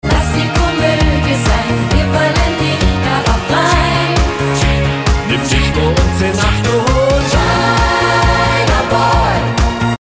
Disco-Pop